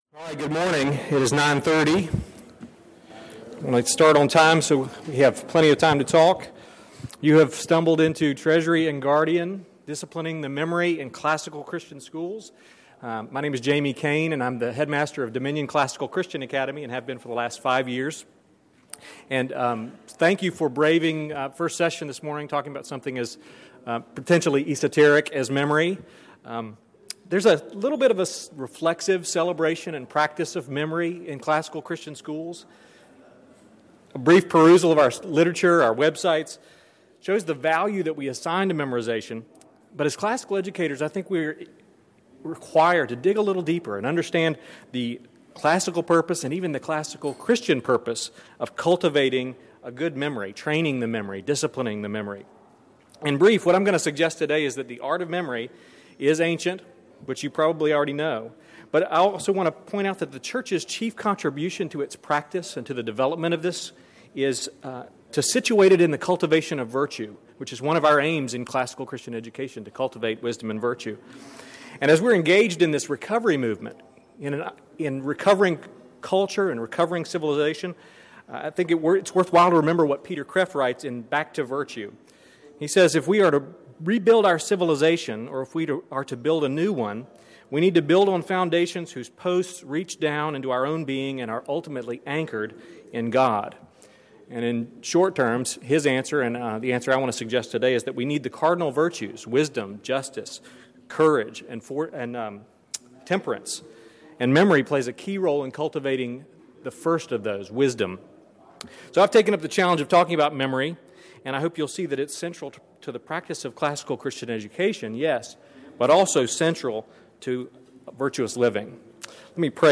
2012 Workshop Talk | 1:01:59 | All Grade Levels